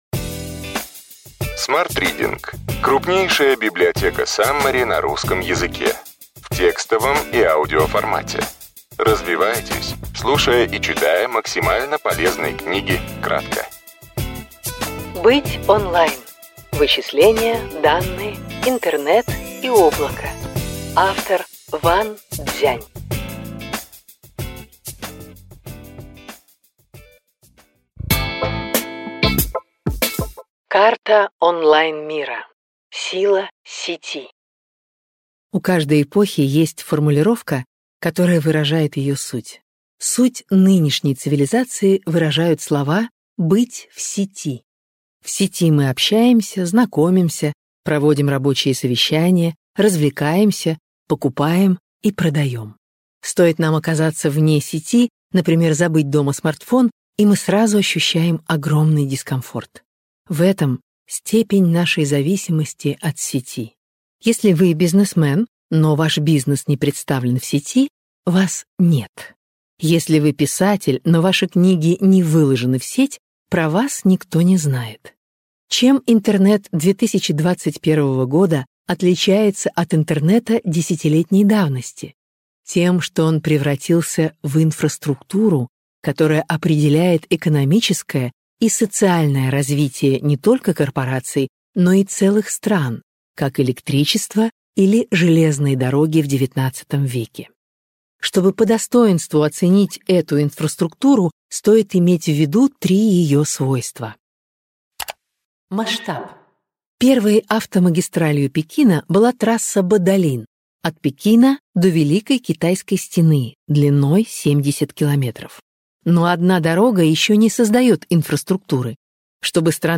Аудиокнига Ключевые идеи книги: Быть онлайн. Вычисления, данные, интернет и облако.